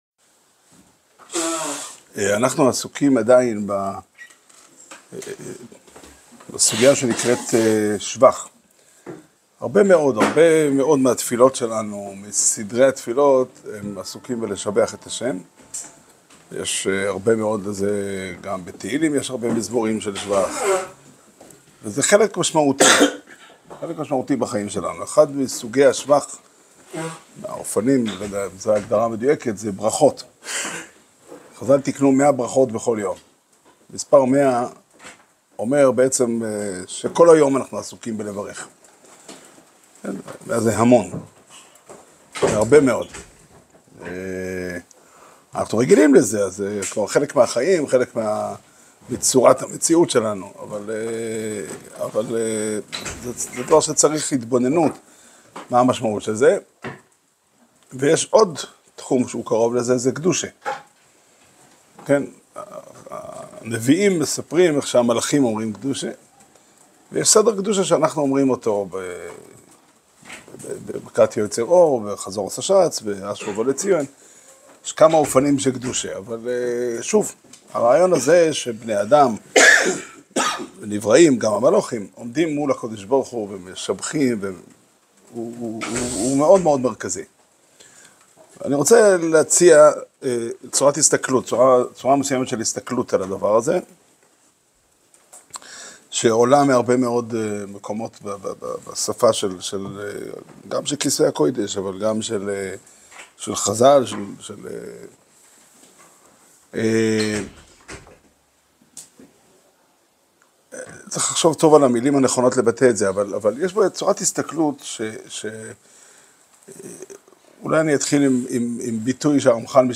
שיעור שנמסר בבית המדרש פתחי עולם בתאריך י"ג חשוון תשפ"ה